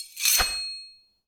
SwordSoundPack
SWORD_22.wav